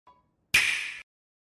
簡潔で明るいタイプの音。